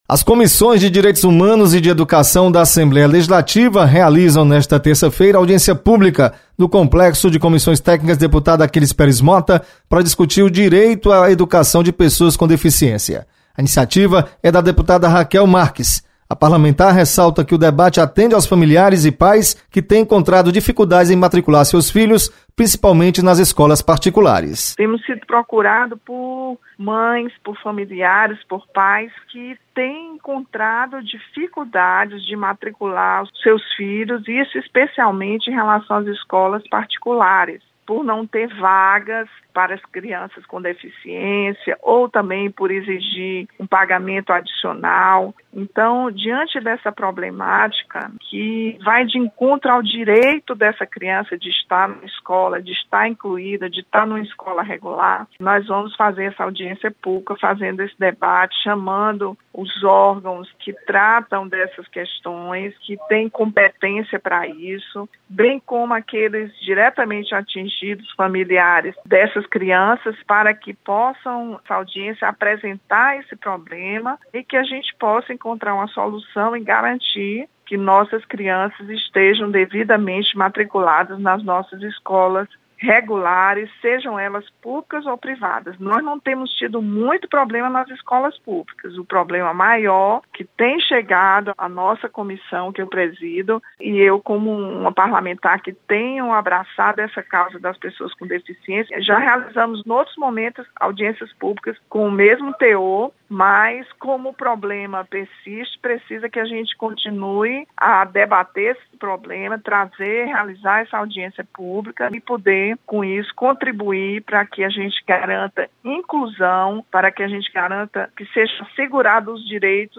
Você está aqui: Início Comunicação Rádio FM Assembleia Notícias Audiência